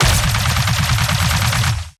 Plasma Rifle
Added more sound effects.